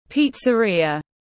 Pizzeria /ˌpiːt.səˈriː.ə/